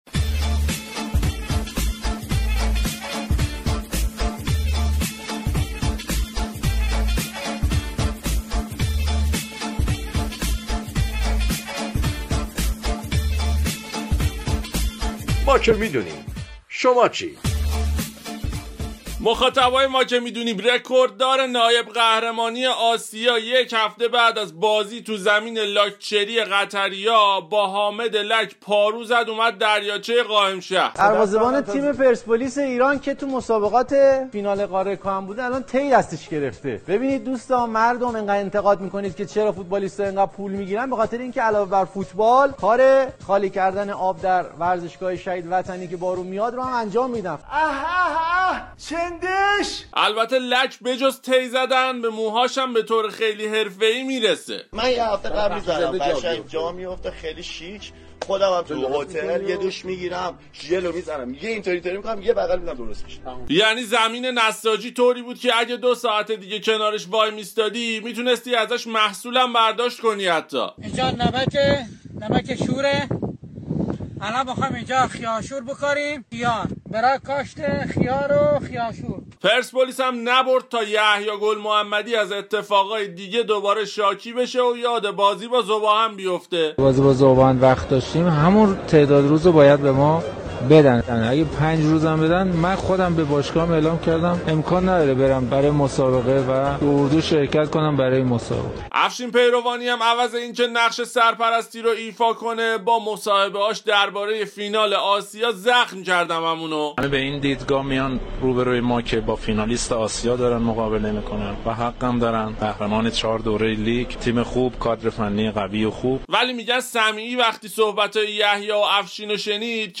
شوخی رادیویی ما که می دونیم با بازی پرسپولیس و نساجی